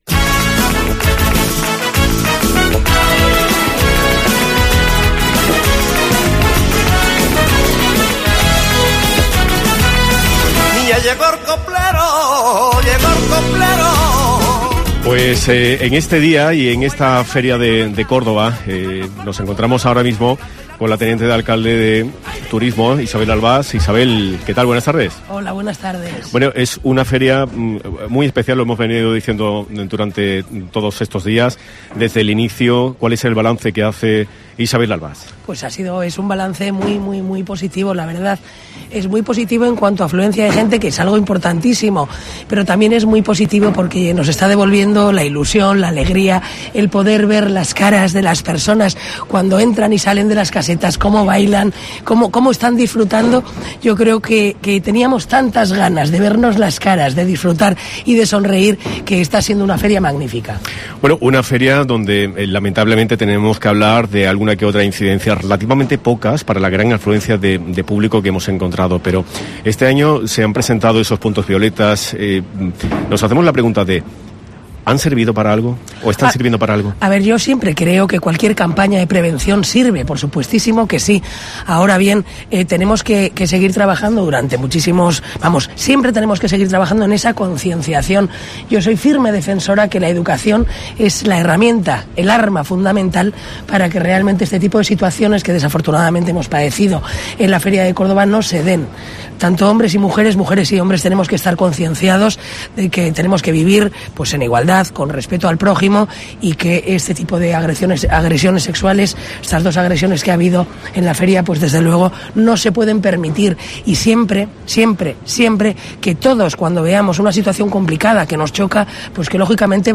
Escucha la entrevista a la delegada de Turismo e Igualdad en el Ayuntamiento de Córdoba, Isabel Albás